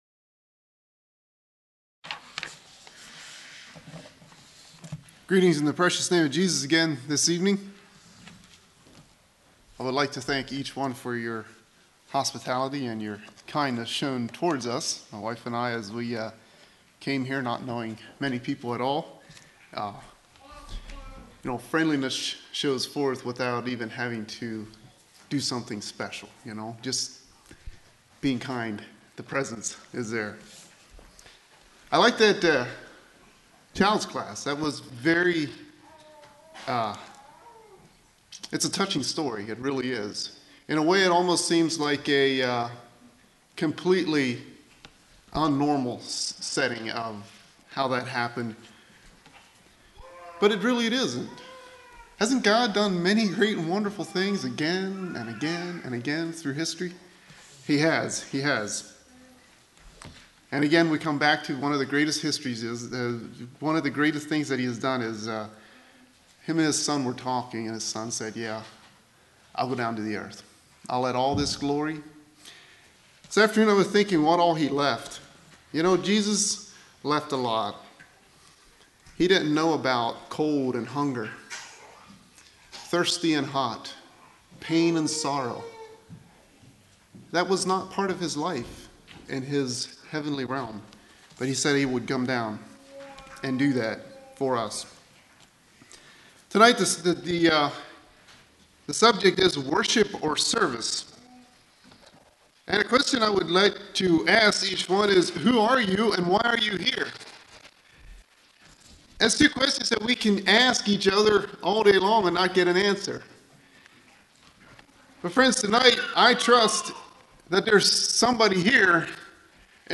2017 Sermon ID